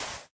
Sound / Minecraft / dig / sand2